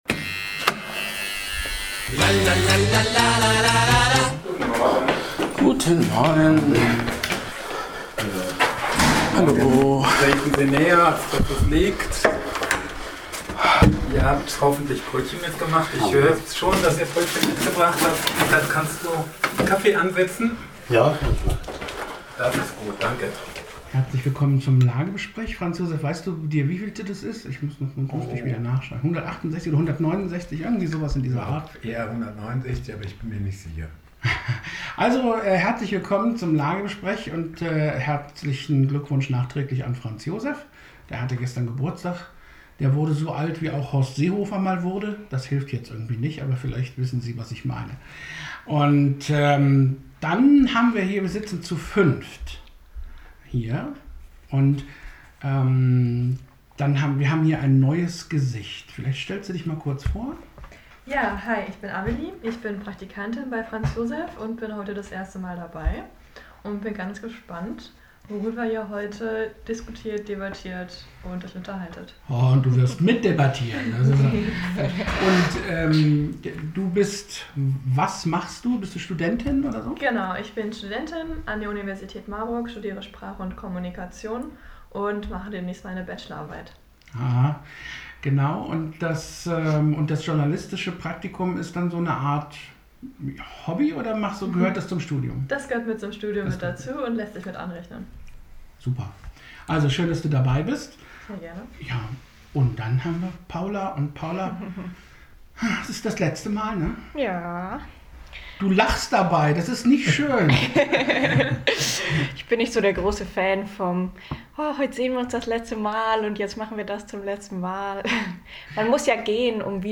Gespräche in der Mittwochsrunde